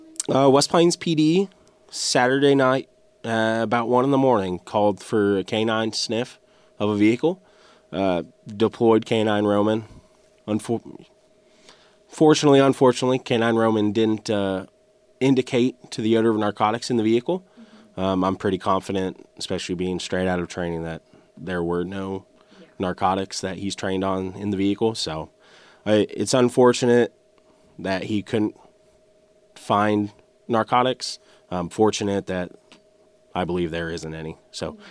Howell County, MO. – Following the current project of Back the Blue K9 Edition, we had the Howell County Sheriff’s Department come in for an interview to describe the day in the life of a K9, specifically one that has only been on the job for a week.